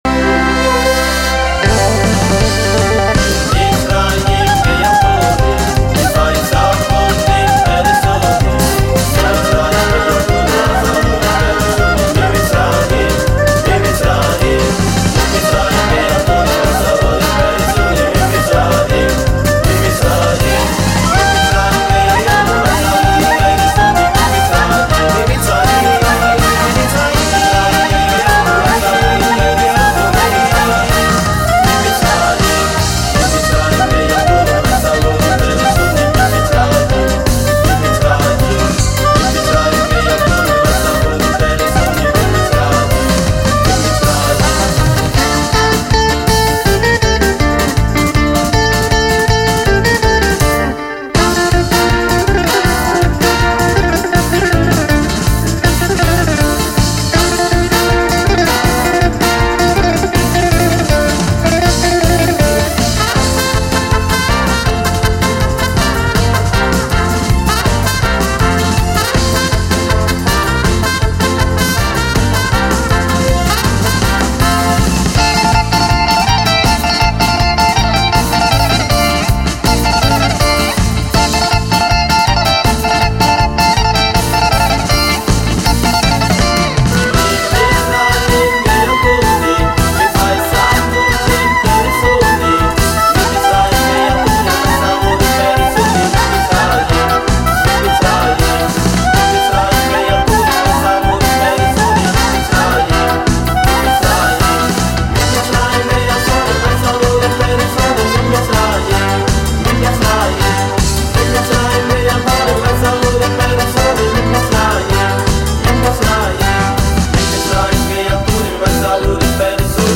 ויש, כמובן, גם ניגון חב"די בדיסק.